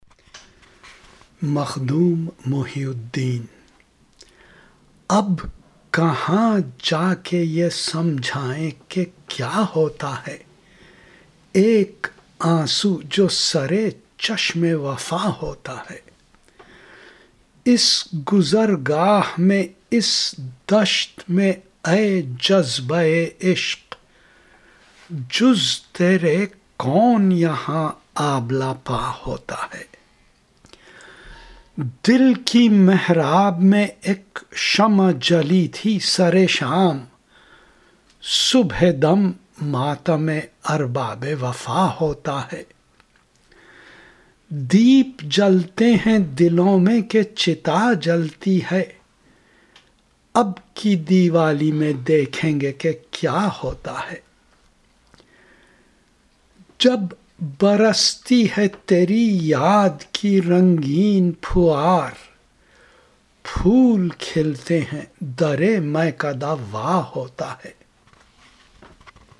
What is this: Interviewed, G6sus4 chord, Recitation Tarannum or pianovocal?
Recitation Tarannum